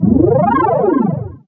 FX_Vinyl013_MPC60.wav